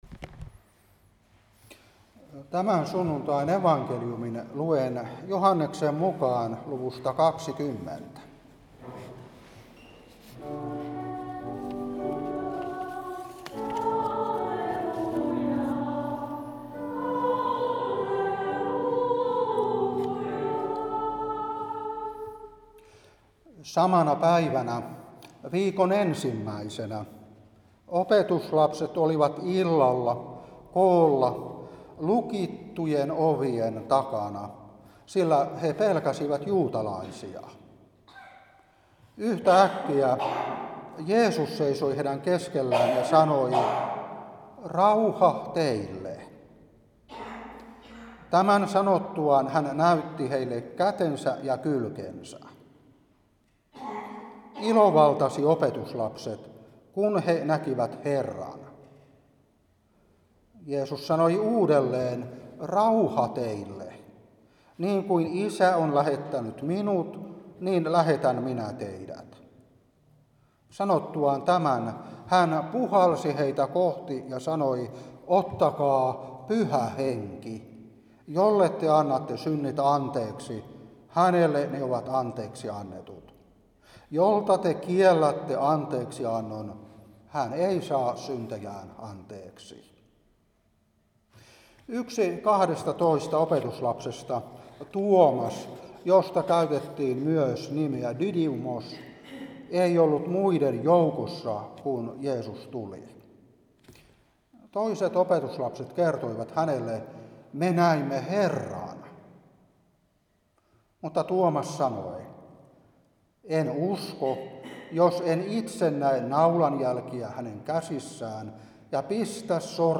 Saarna 2025-4.